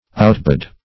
outbud - definition of outbud - synonyms, pronunciation, spelling from Free Dictionary Search Result for " outbud" : The Collaborative International Dictionary of English v.0.48: Outbud \Out*bud"\, v. i. To sprout.